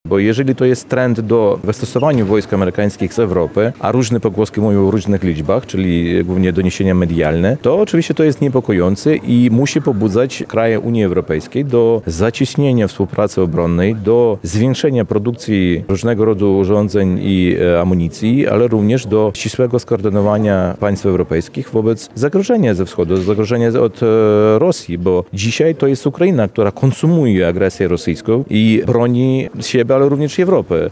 – Jeszcze w tym miesiącu mają rozpocząć się pierwsze prace ekshumacyjne Ofiar Wołynia w obwodzie tarnopolskim – informację przekazał ambasador Ukrainy w Polsce Vasyl Bodnar. Ambasador był gościem kolejnego spotkania z cyklu „Rozmowy dyplomatyczne” na Uniwersytecie Marii Curie-Skłodowskiej.